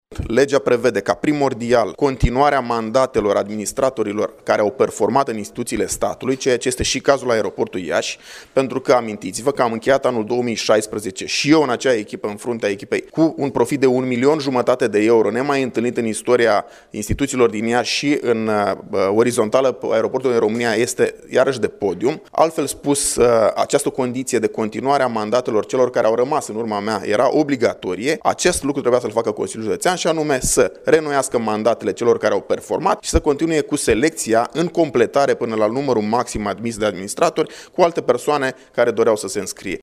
Preşedintele PNL Iaşi, Marius Bodea, a mai spus că în momentul în care o societate aflată în subordinea unei autorităţi locale sau judeţene are un management performant atestat, atunci automat conducerea poate să-şi prelungească mandatul fără concurs: